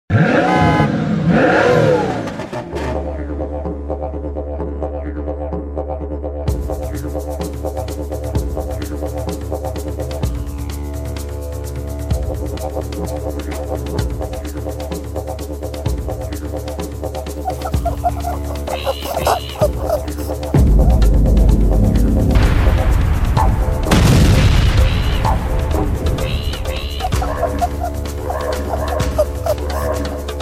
Koala Mom And Cub's Warning Sound Effects Free Download